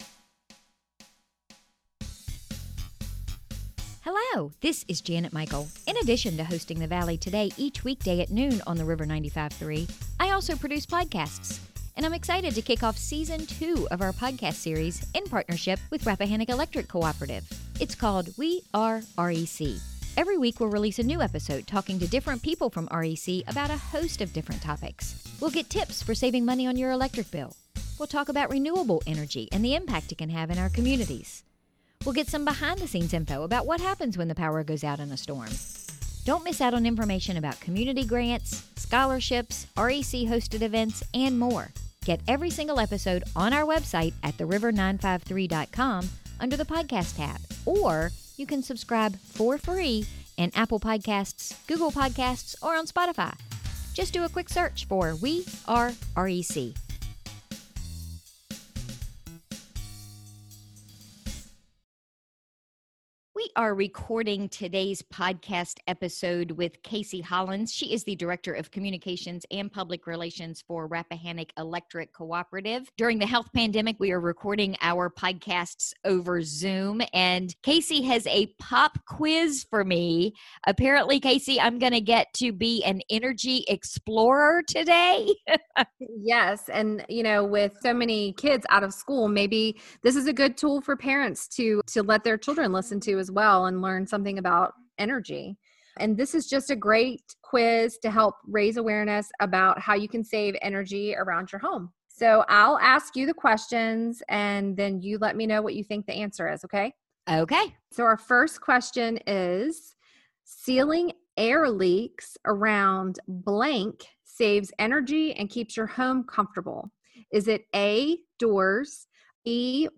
We recorded today’s episode via Zoom